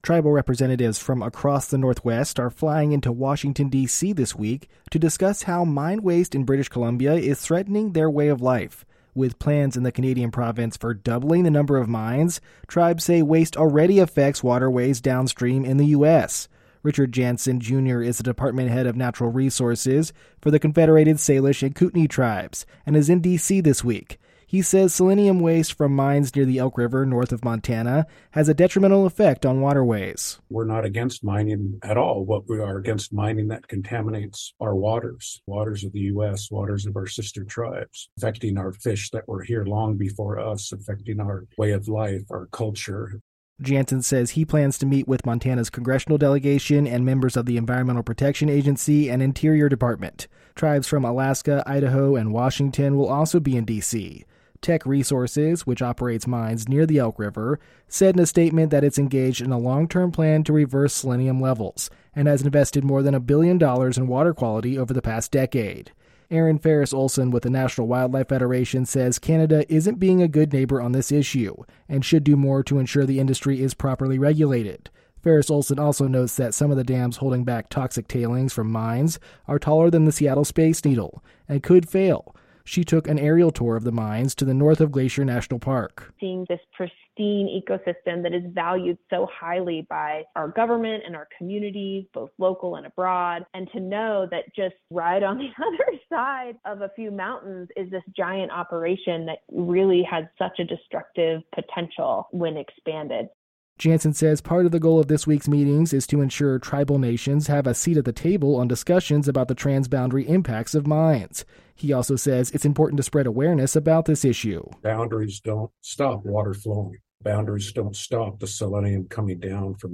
(Pronouncers: Salish is "SAY-lish;" Kootenai is "KOOT-nee.")